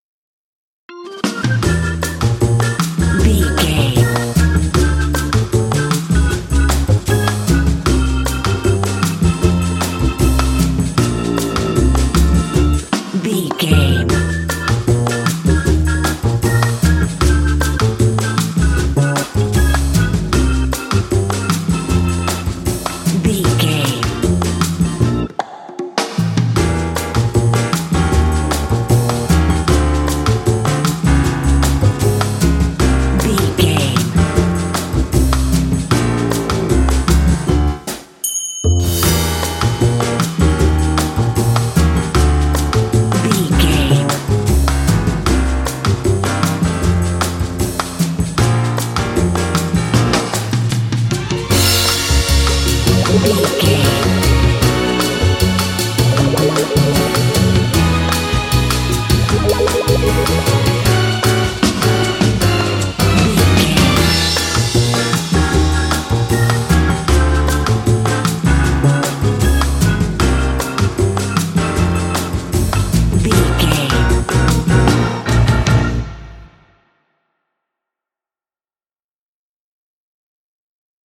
Uplifting
Aeolian/Minor
percussion
flutes
piano
orchestra
double bass
silly
circus
goofy
comical
cheerful
perky
Light hearted
quirky